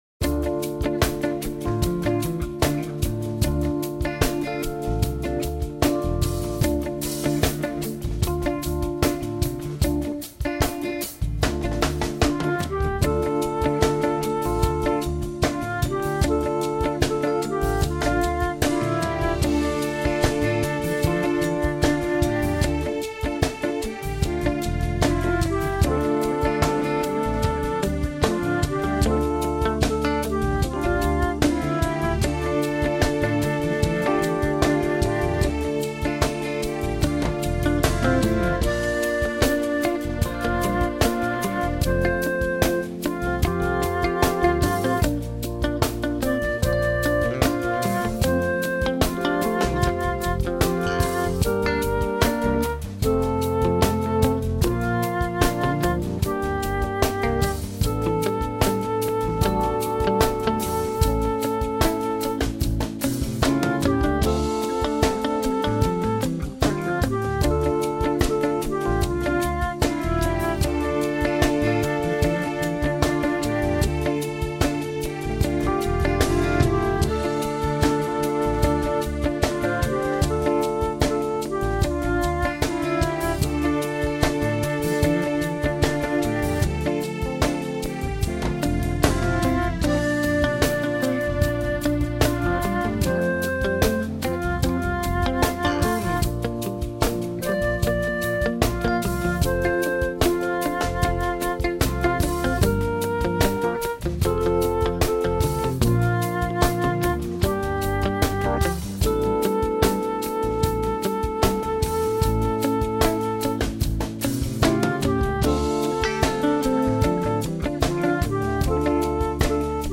My backing adds rhythmic elements: